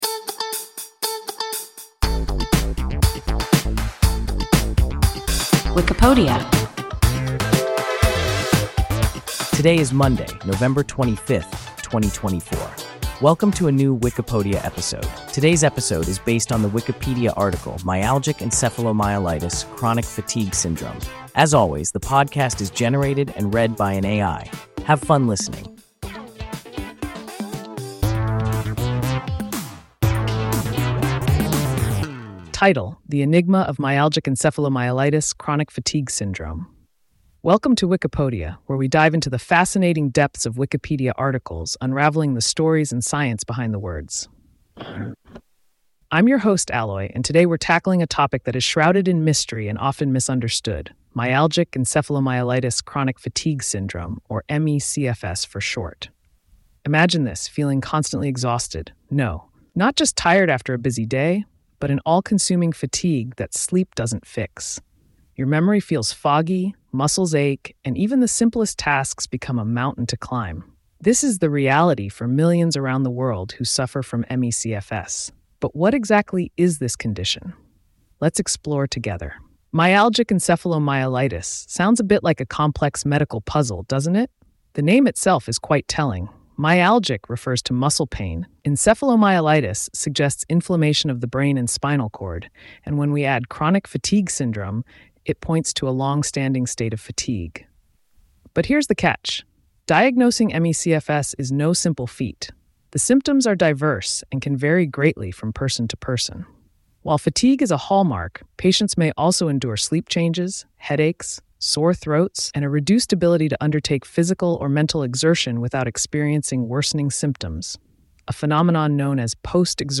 Myalgic encephalomyelitis/chronic fatigue syndrome – WIKIPODIA – ein KI Podcast